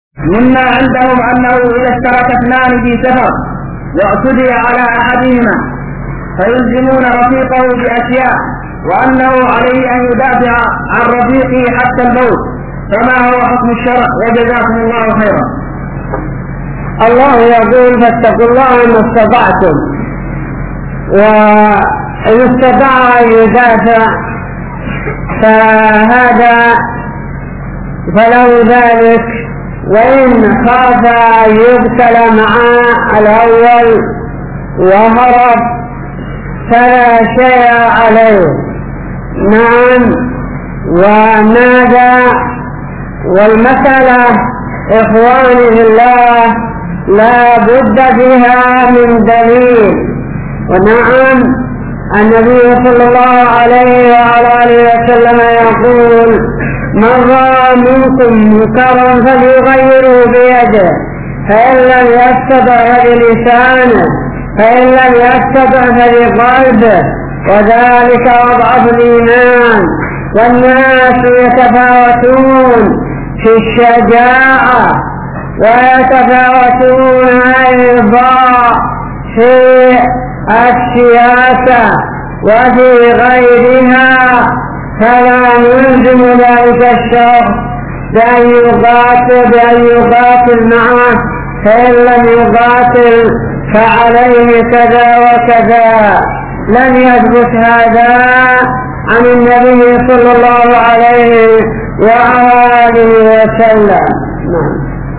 من اعتدي عليه ورفيقه معه في السفر | فتاوى الشيخ مقبل بن هادي الوادعي رحمه الله